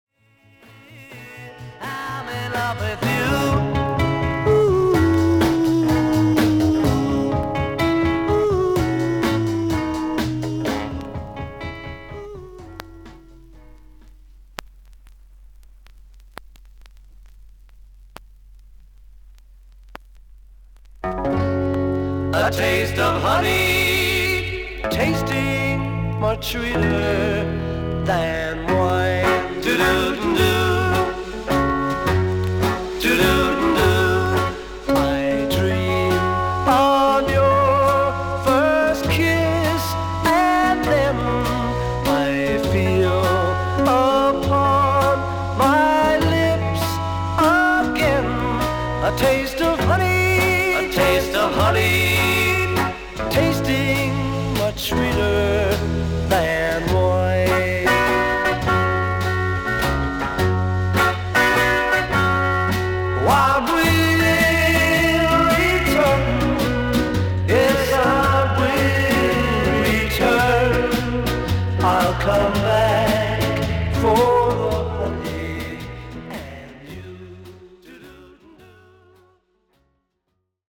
B4終わり〜B5序盤に4mmのキズ、少々周回ノイズあり。曲間は目立ちますが曲中は目立ちません。
少々サーフィス・ノイズあり。クリアな音です。